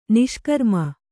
♪ niṣkarma